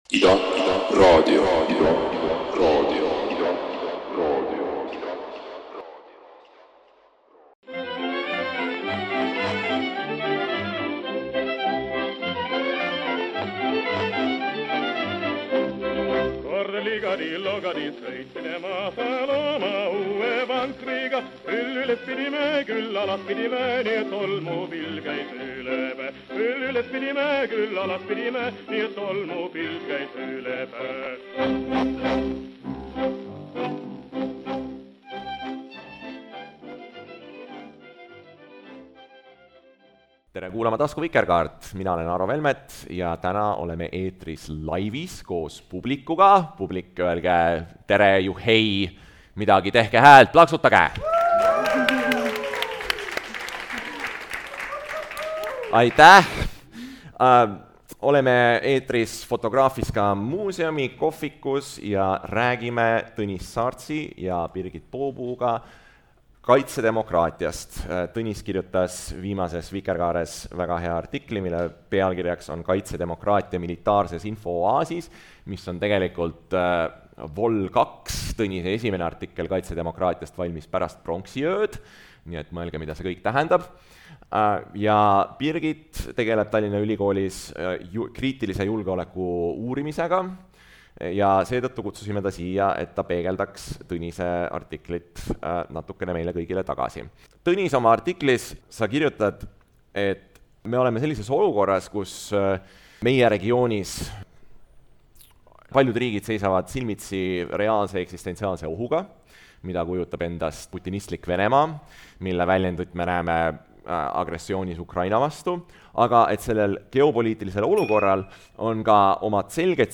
Hea vestlus tekitas ka publiku hulgas palju küsimusi – seda kõike saate nüüd taskuhäälingus järele kuulata.